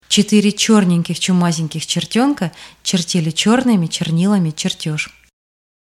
Russian tongue-twister that may help you to practice pronouncing the letter Ч: Четыре чёрненьких, чумазеньких чертёнка Чертили чёрными чернилами чертёж.